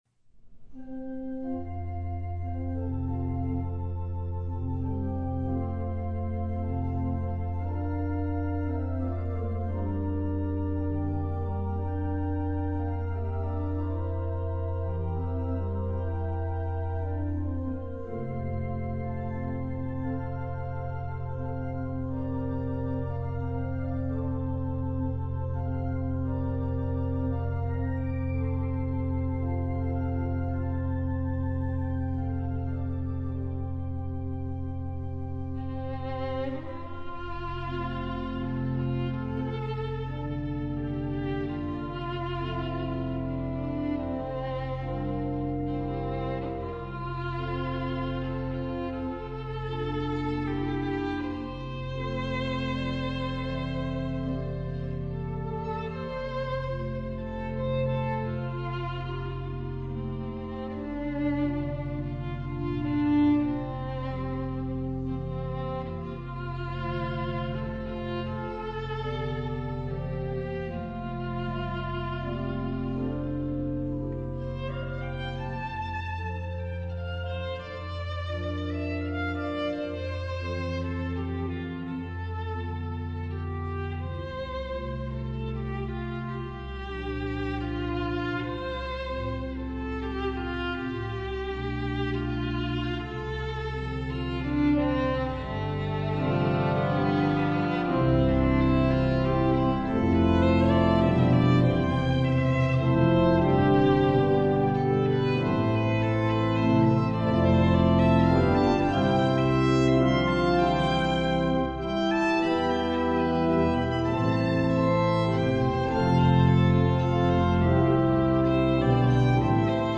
Voicing: Violin W/o